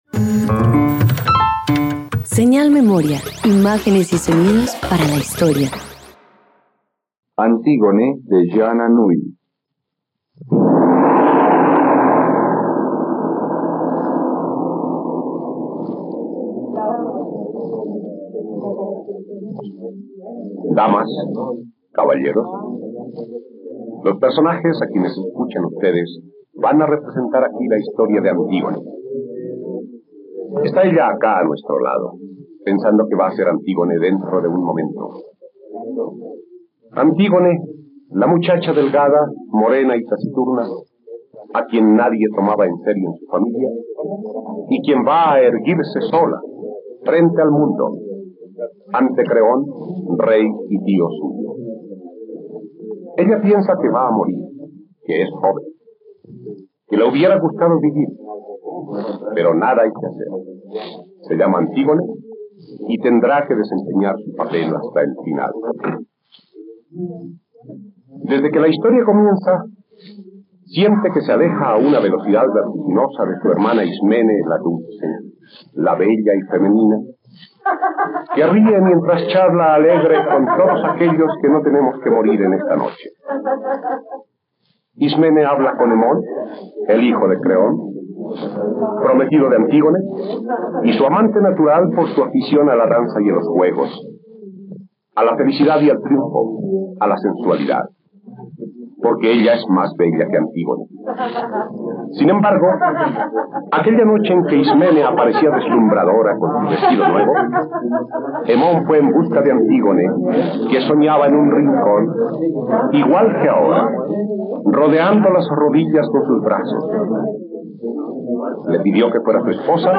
..Radioteatro. Escucha la adaptación del mito griego de Antígona del dramaturgo griego Sófocles, disponible en la plataforma de streaming RTVCPlay.